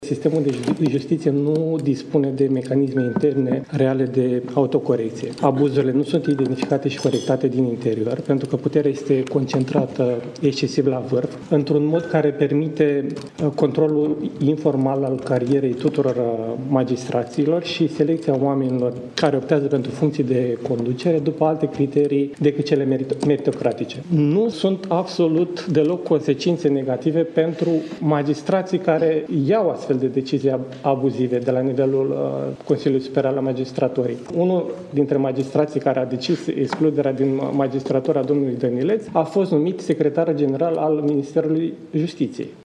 „Sistemul este profund disfuncțional, iar abuzurile nu pot fi sancționate. Puterea este concentrată la vârf”, este declarația făcută luni dimineață de judecătorul Liviu Cârneciu, de la Tribunalul Covasna, la dezbaterea organizată de președintele Nicușor Dan la Palatul Cotroceni, în contextul dezbaterilor declanșate de documentarul Recorder „Justiție capturată”.
Întâlnirea de la Palatul Cotroceni cu magistrați și alți actori din sistemul judiciar